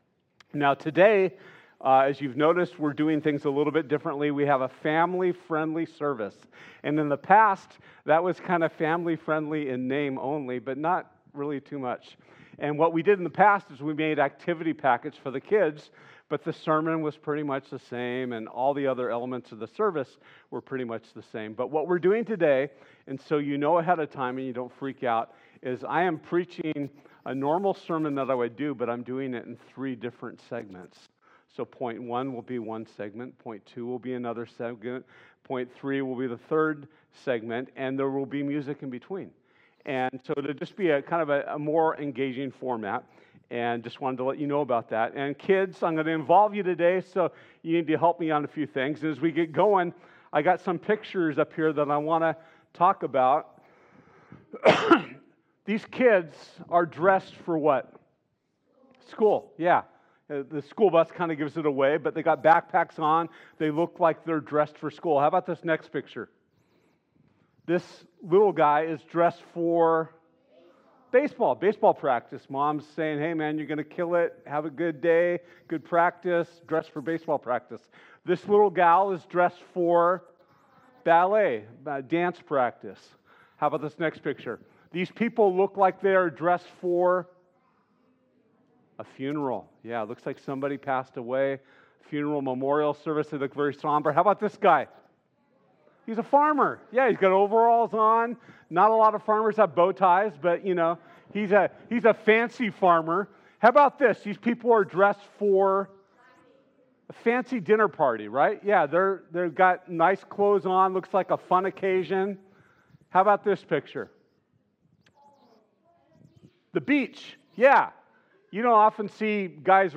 This Sunday at CBC Ventura, we’ll be implementing our first real family-friendly service!